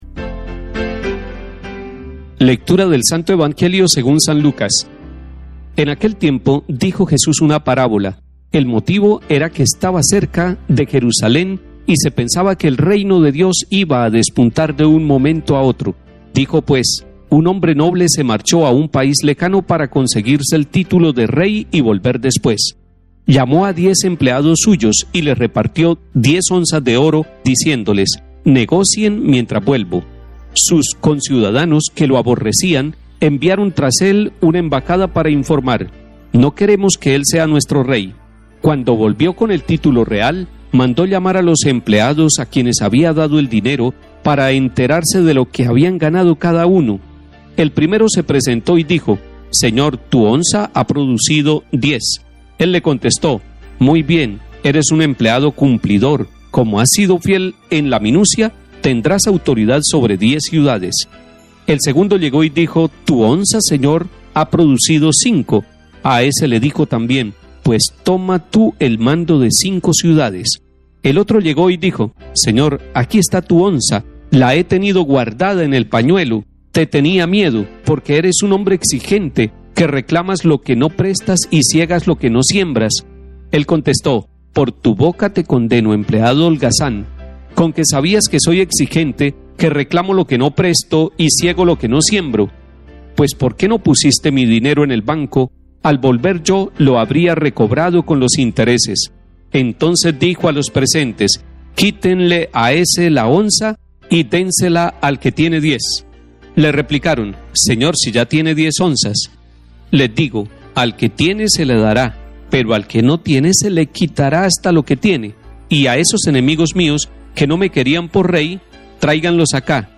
Comentario del Cardenal Mons. Luis José Rueda Aparicio, Arzobispo de Bogotá y Primado de Colombia.